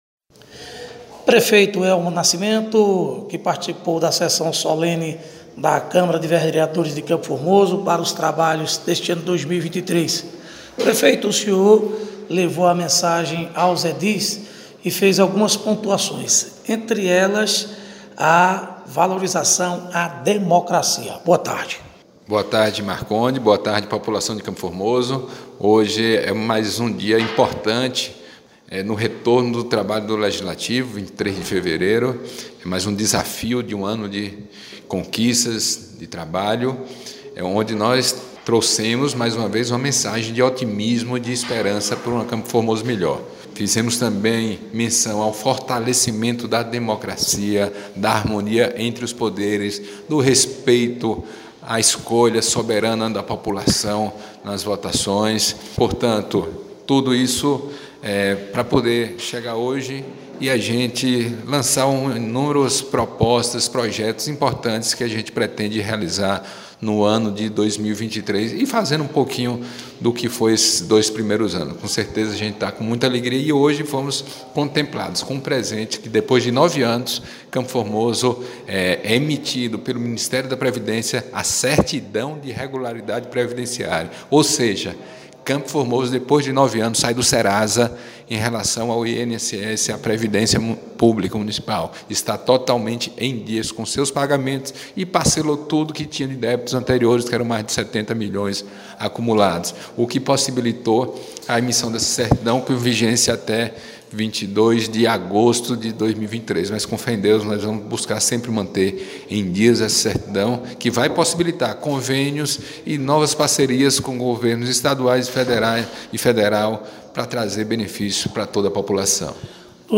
Entrevista ao vivo com o Prefeito do município de Campo formoso Elmo Nascimento